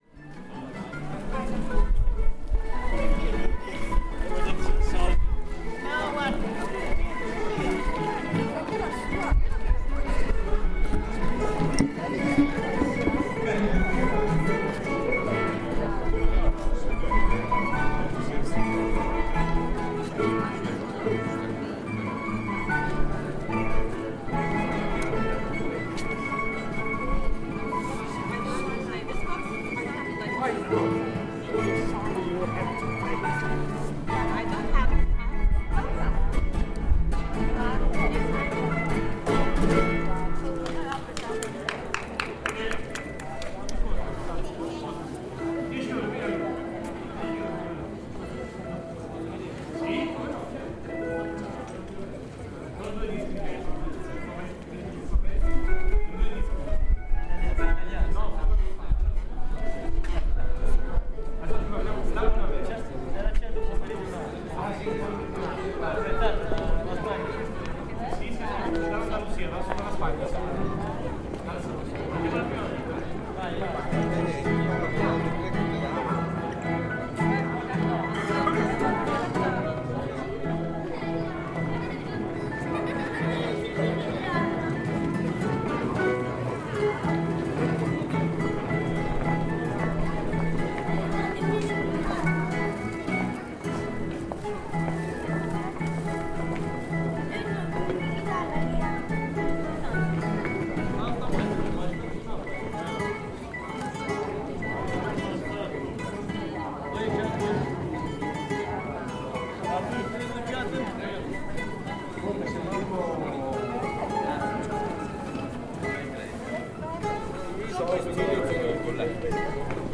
String-playing buskers perform part of the "Four Seasons" by Vivaldi to entertain passers-by, Venice.